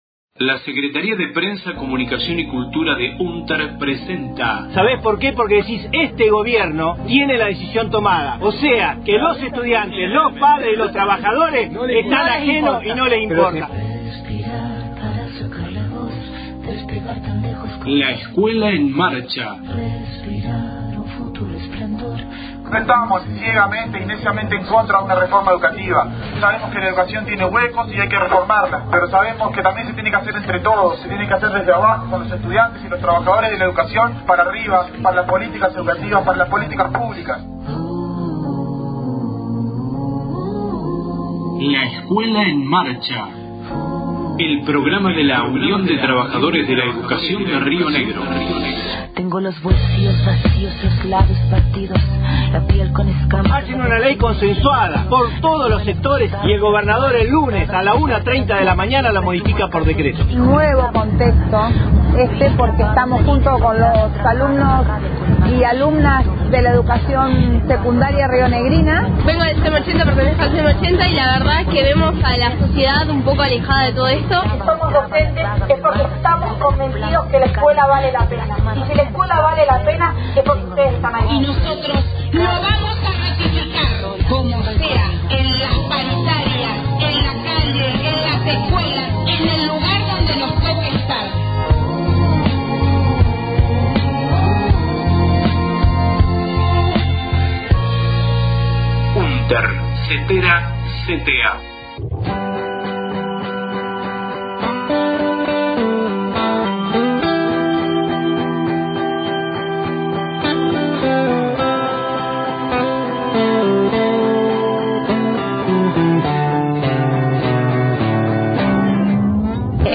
LEEM 30/06/19 Voces “Pañuelazo” realizado en Roca – Fiske Menuco del 28/05/19 por la presentación del proyecto IVE en el Congreso de la Nación.
• Audio de conferencia de prensa realizada el 27/05/19 con sindicatos y organizaciones sociales que convocaron al paro nacional del 29 de mayo